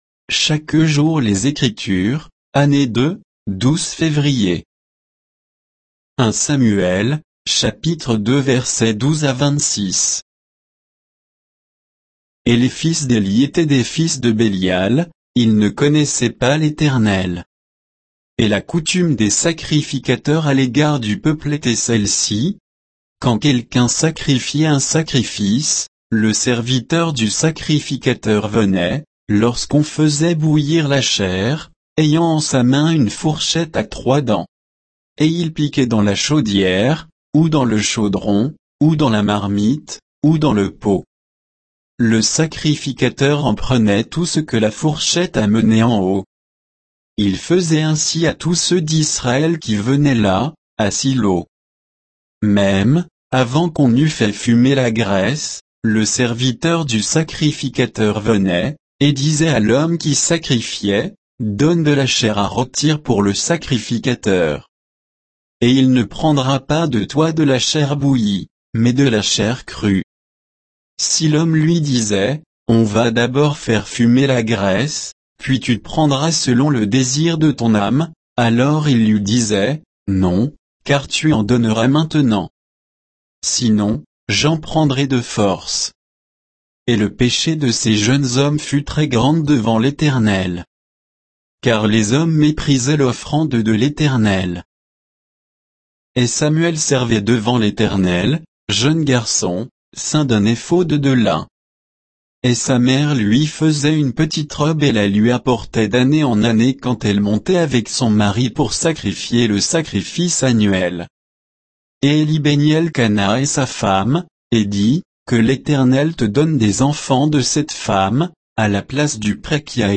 Méditation quoditienne de Chaque jour les Écritures sur 1 Samuel 2